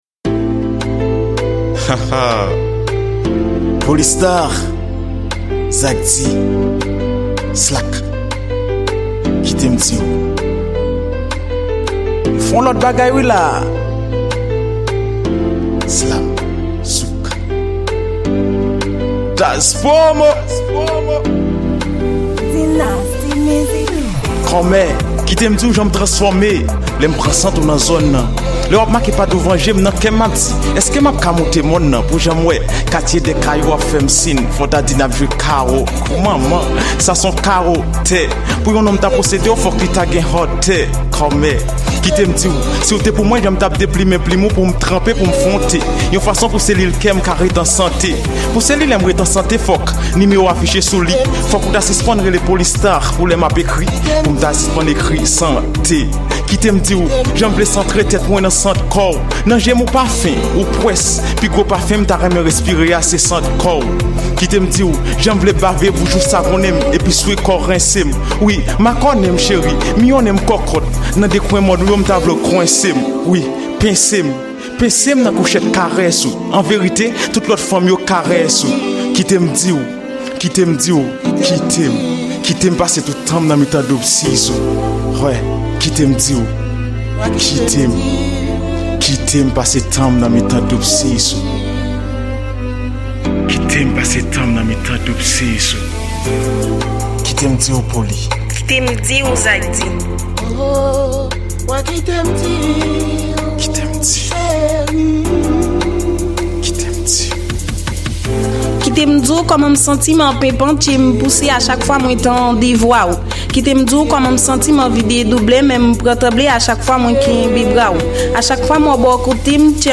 Genre: Slam